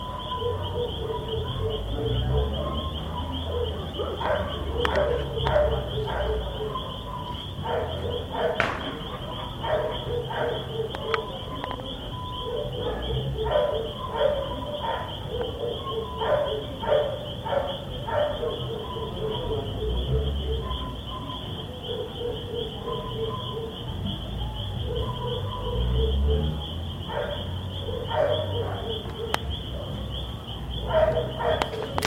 Rana Piadora (Leptodactylus latinasus)
Ejemplares vocalizando desde una Zanja.
Localidad o área protegida: Pilar
Condición: Silvestre
Certeza: Vocalización Grabada
Rana-Piadora.mp3